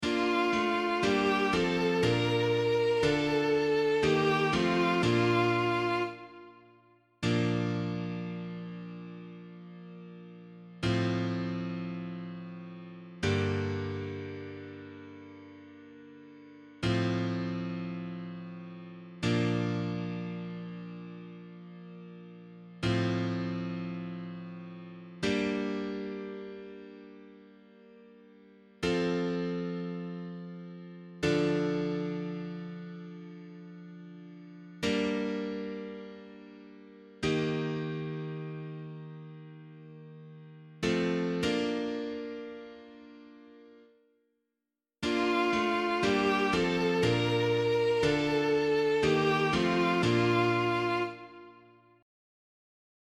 Responsorial Psalm     Psalm 69 (68): 8-10, 14+17, 33-35
B♭ major